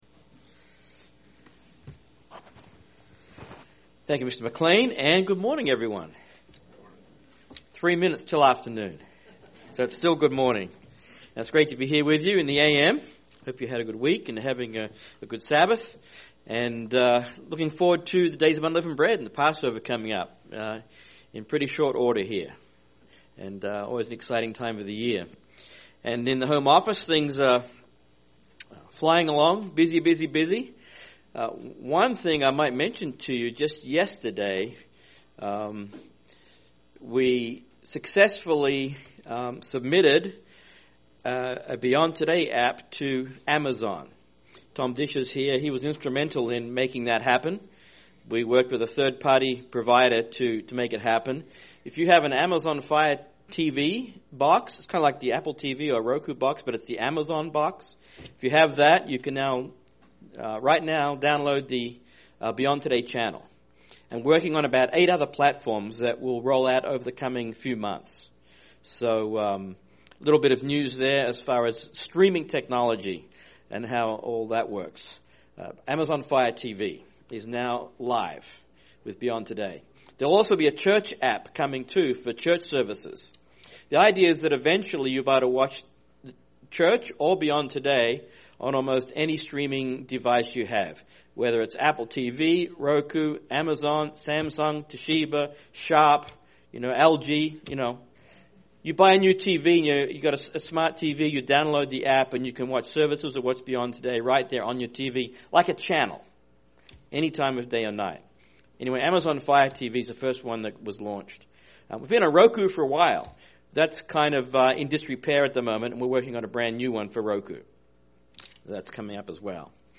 This sermon examines Exodus 34 and Psalm 32 to show how deep is God's mercy and forgiveness for those who seek Him.